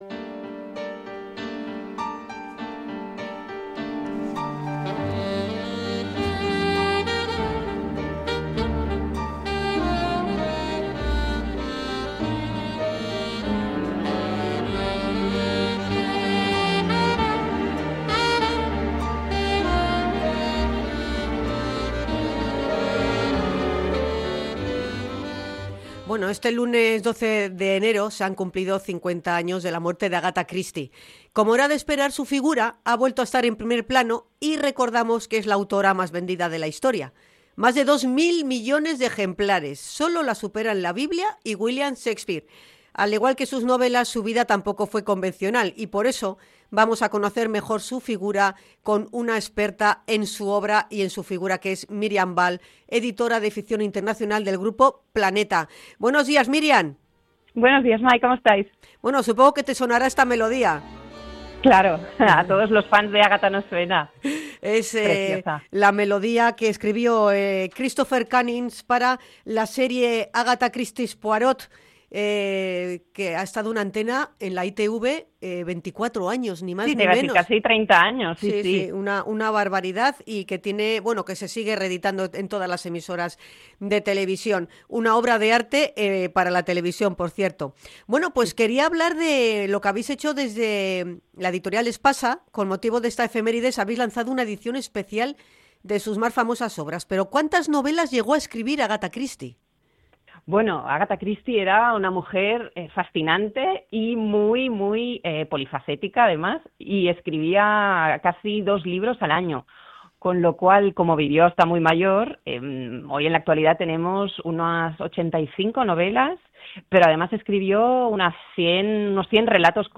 INT.-AGATHA-CHRISTIE-ANIVERSARIO.mp3